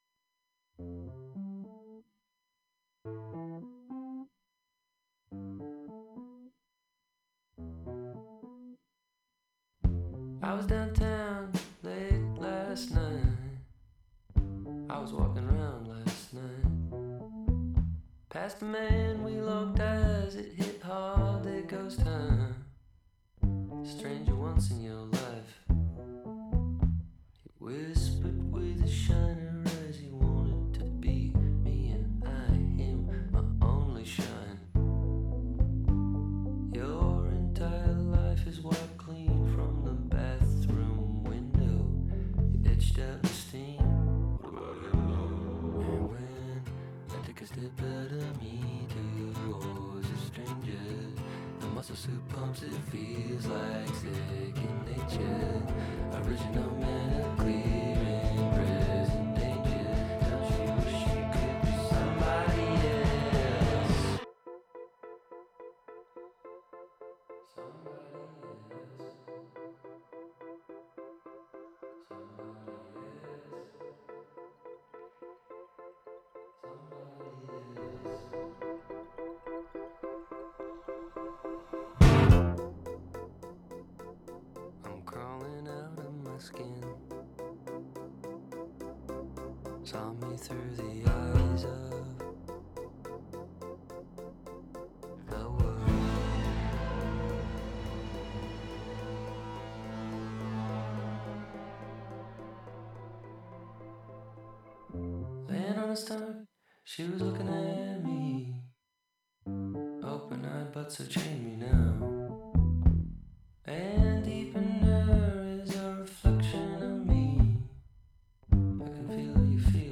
Each broadcast features candid conversations with both professional and aspiring artists, uncovering the heart of their creative process, the spark of their inspiration, and the journey that brought them into the art scene. From painters and sculptors to musicians and writers, we celebrate the richness of artistic expression in our community and beyond.